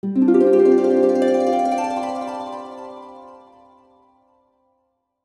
Magical Harp (8).wav